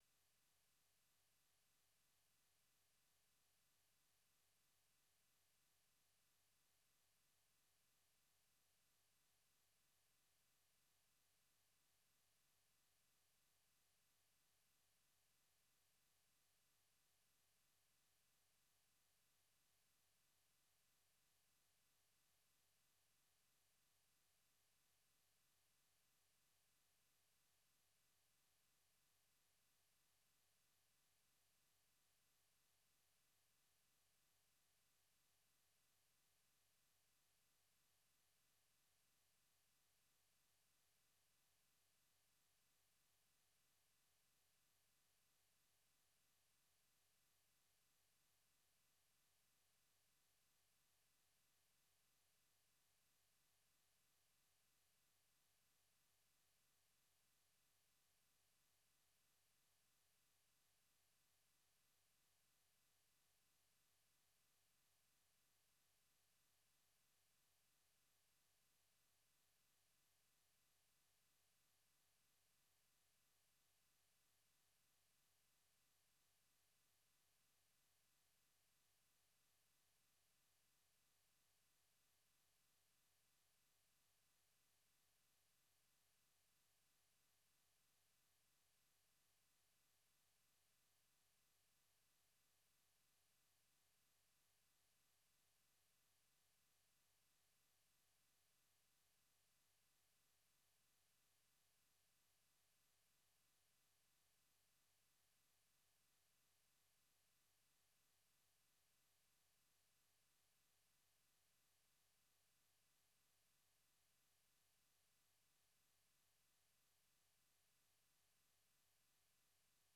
Daybreak Africa is a 30-minute breakfast show looks at the latest developments on the continent and provides in-depth interviews, and reports from VOA correspondents.